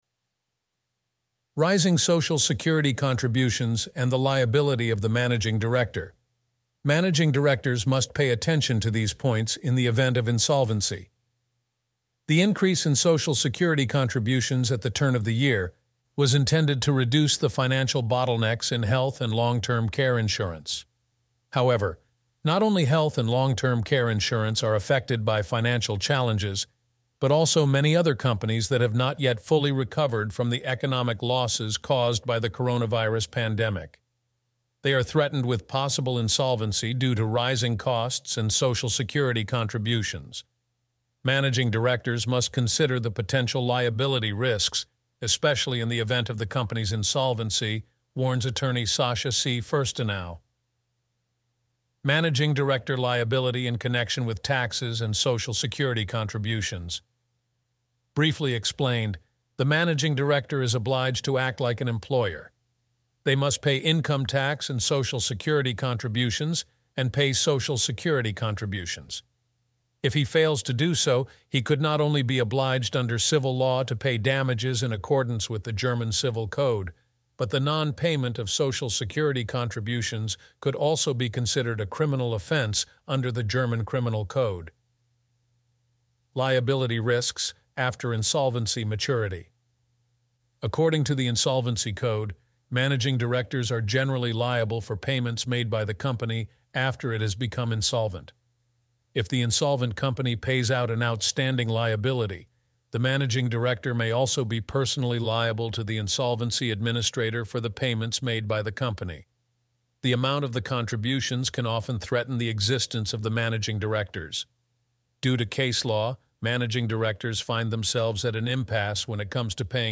Read out the article